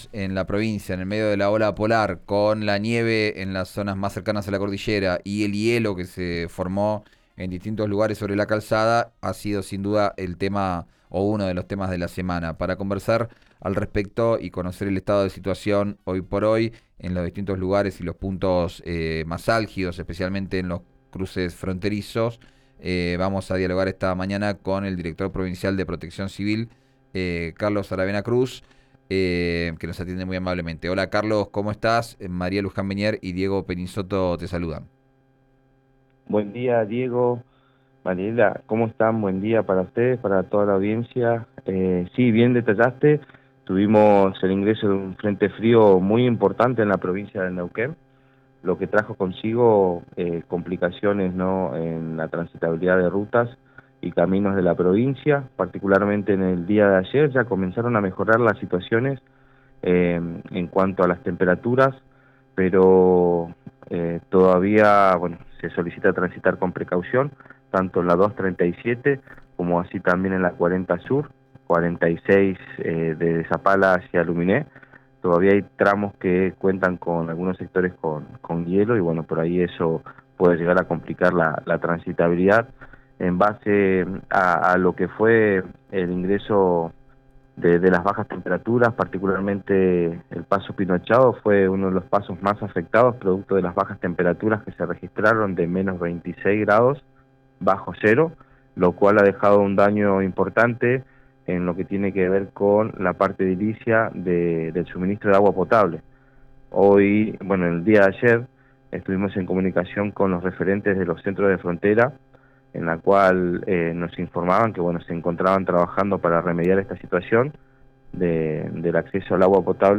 Escuchá al director de Defensa Civil de Neuquén, Carlos Cruz, en RÍO NEGRO RADIO